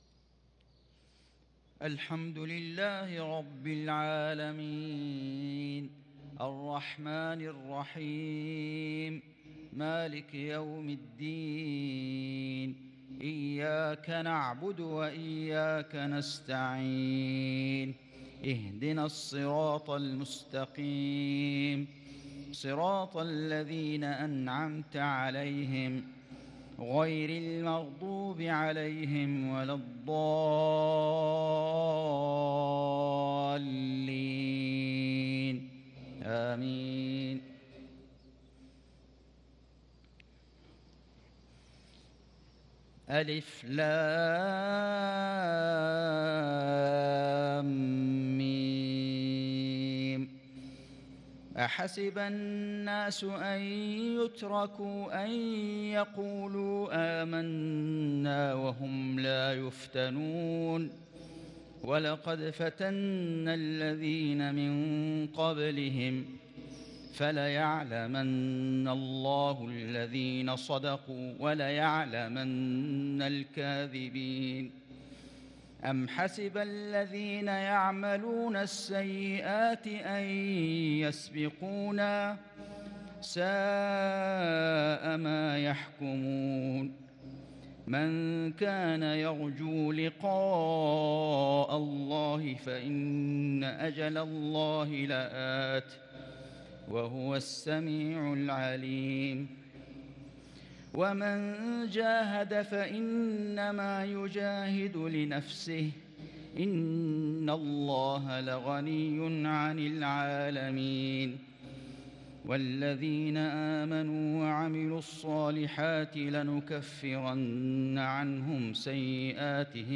صلاة المغرب للقارئ فيصل غزاوي 4 جمادي الأول 1443 هـ